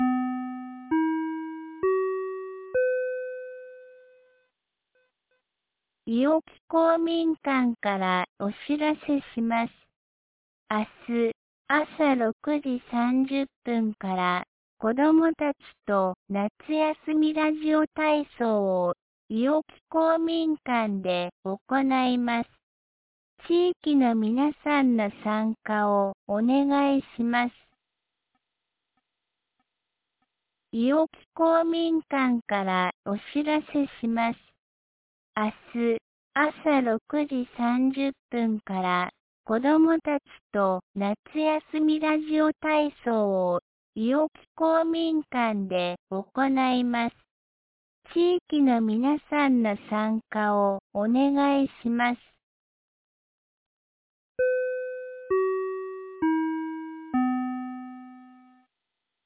2025年07月21日 17時11分に、安芸市より伊尾木へ放送がありました。